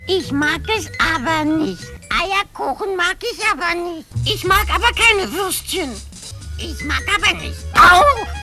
"Ich mag nicht"-Schlumpf: